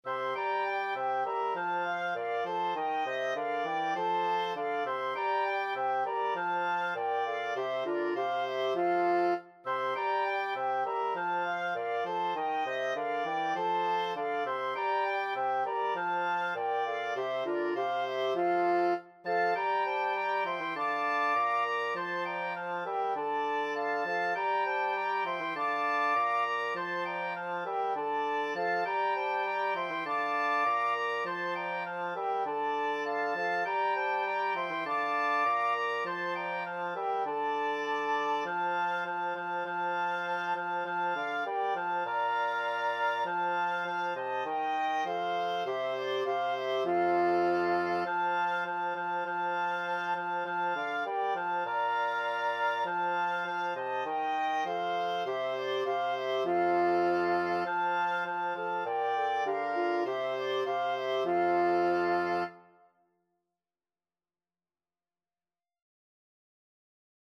Free Sheet music for Wind Quartet
FluteOboeClarinetBassoon
F major (Sounding Pitch) (View more F major Music for Wind Quartet )
4/4 (View more 4/4 Music)
Wind Quartet  (View more Intermediate Wind Quartet Music)
Classical (View more Classical Wind Quartet Music)